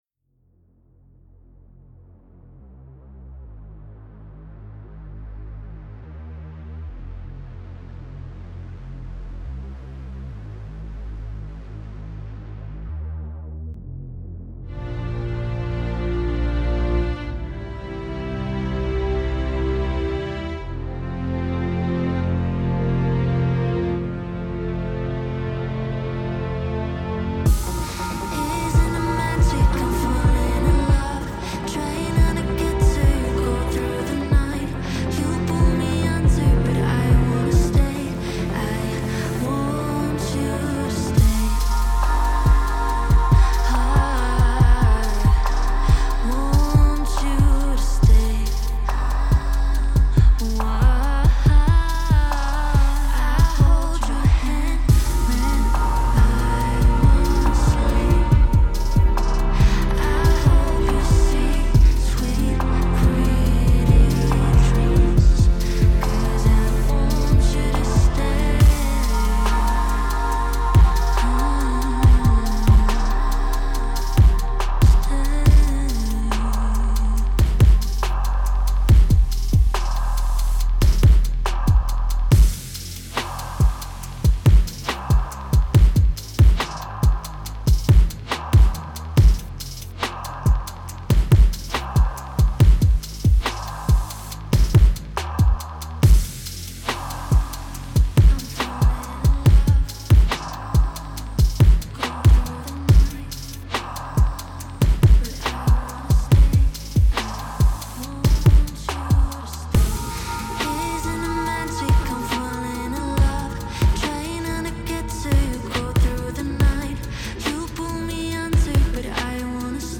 Tempo 140BPM (Allegro)
Genre Melacholic House
Type Vocal Music
Mood Melancholic
Render Loudness -6 LUFS